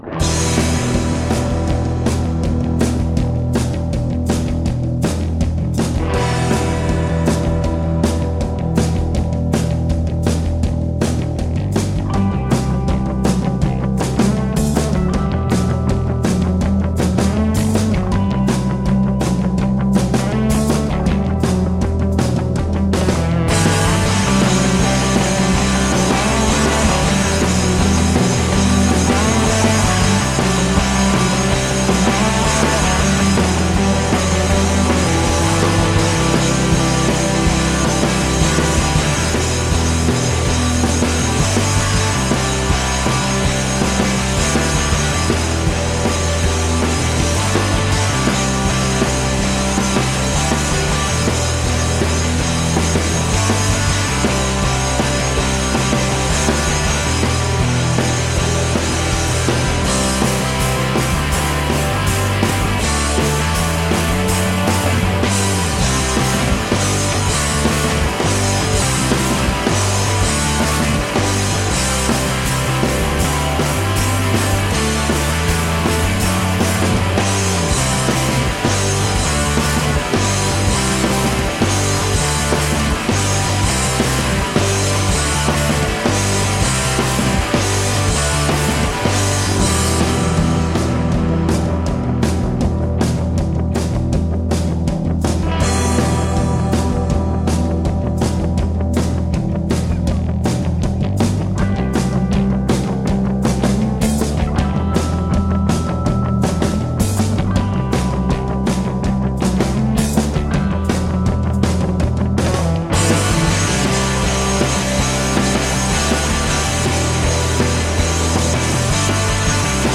High energy rock and roll.
at a warehouse in Northern Virginia
Tagged as: Hard Rock, Metal, Punk, High Energy Rock and Roll